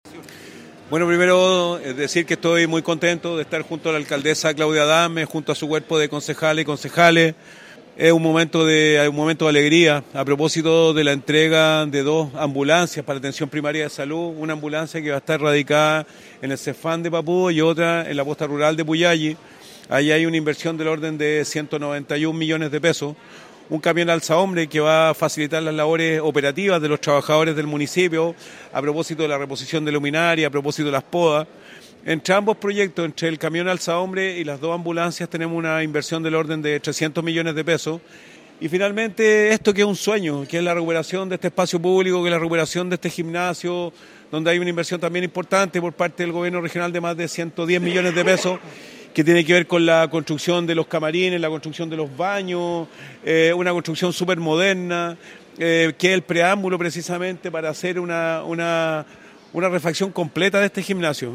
gobernador-mundaca-proyectos-papudo.mp3